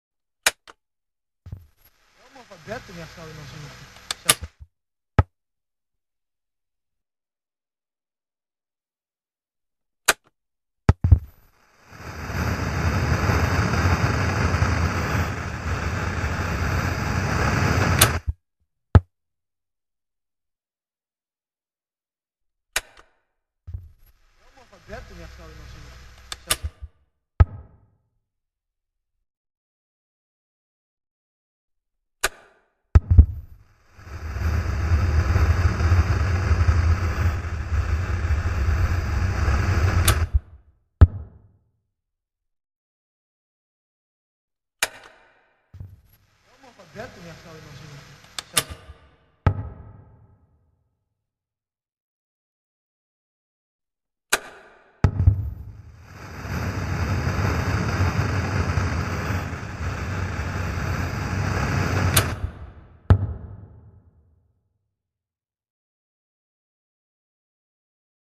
开关-人类动作-图秀网
图秀网开关频道，提供开关音频素材。